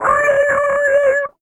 Index of /90_sSampleCDs/Zero-G - Total Drum Bass/Instruments - 2/track42 (Guitars)